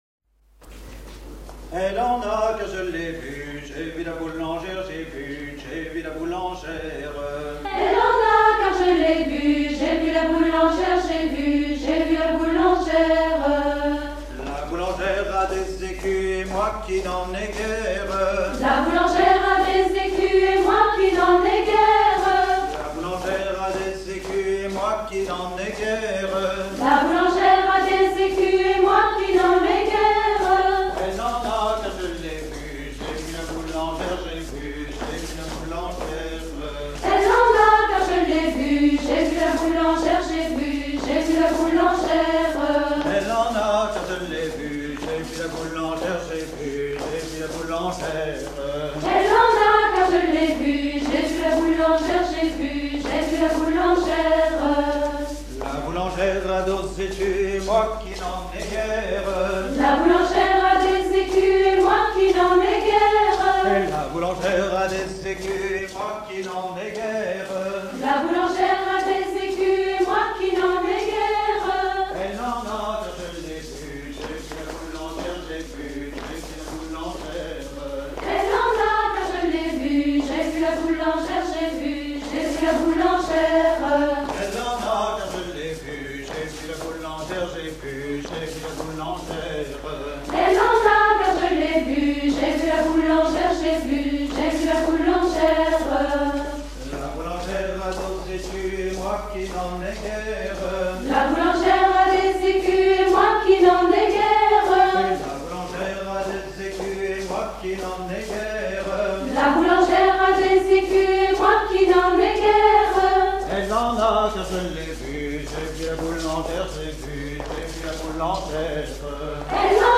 Rondes enfantines à baisers ou mariages
Dix danses menées pour des atelirs d'apprentissage
Pièce musicale inédite